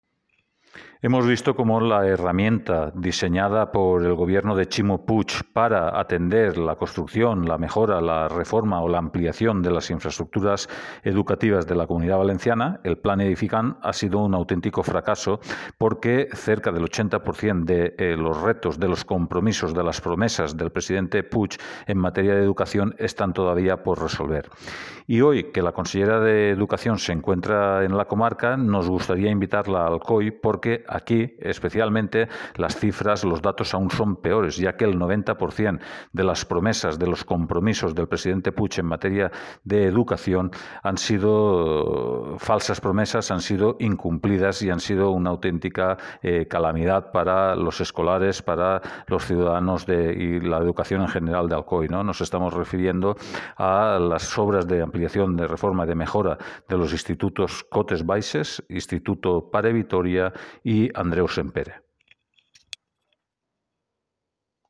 corte-voz-nando-pastor-edificantmpeg.mp3